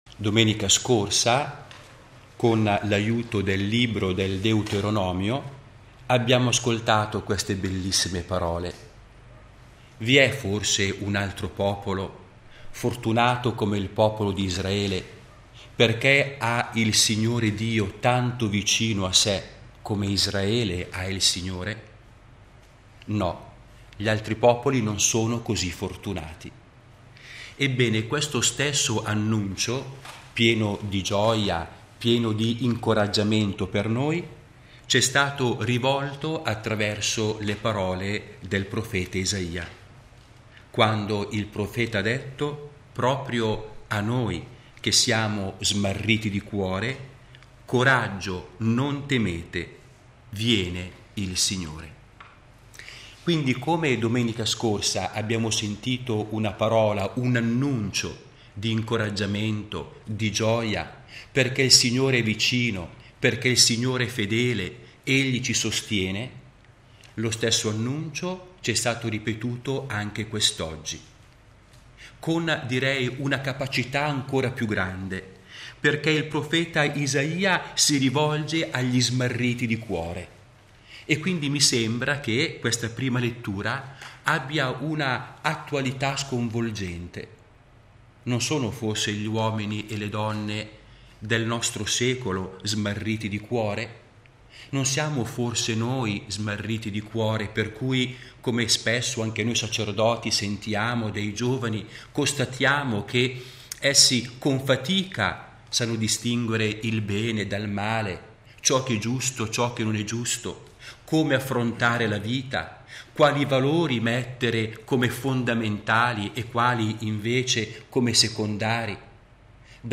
Santa Messa in collegamento RAI-Radio1
Ogni Domenica alle 9.30 dalla Cappella Leone XIII all'interno dei Giardini Vaticani, viene trasmessa la Santa Messa secondo le intenzioni del Sommo Pontefice Benedetto XVI.